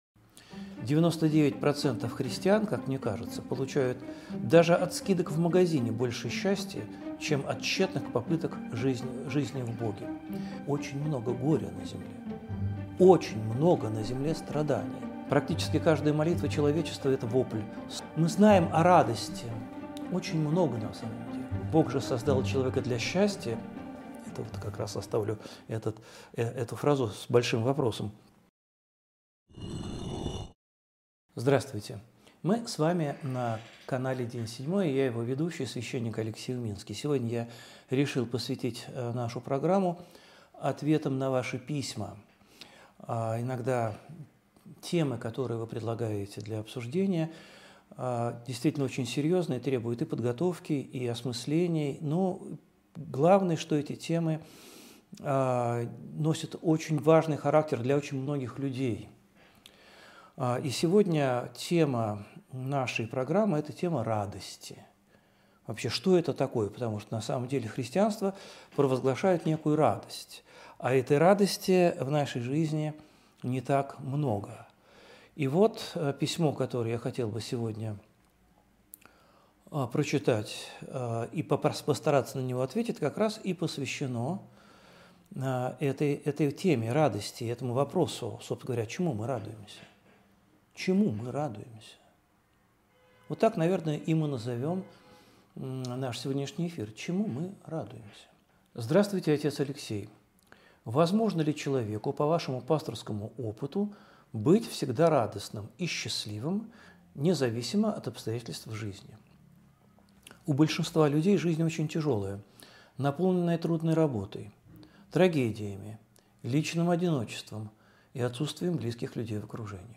Эфир ведёт Алексей Уминский